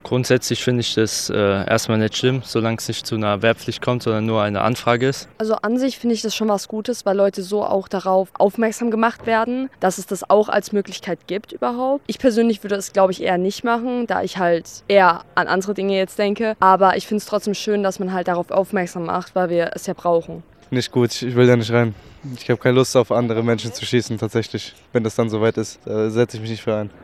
Es sei "nicht irgendein Gesetz, sondern ein Riesenschritt nach vorne", sagte Verteidigungsminister Boris Pistorius in der Pressekonferenz danach.